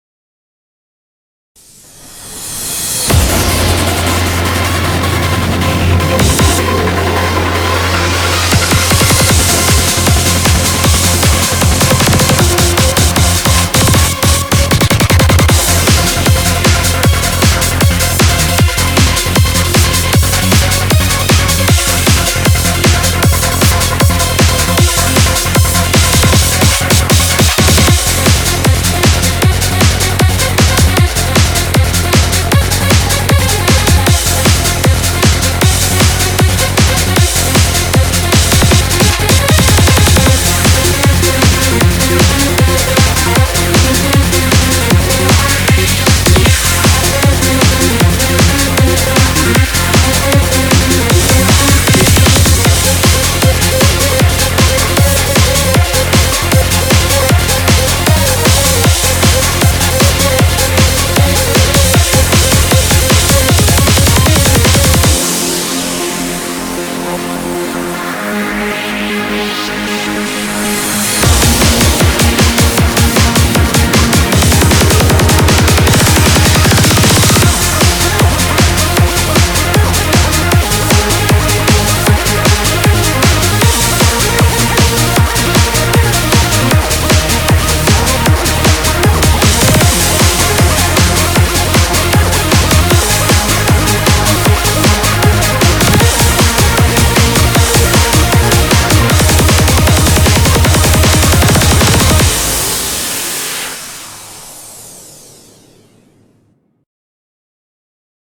BPM155
Audio QualityPerfect (High Quality)
techno-style song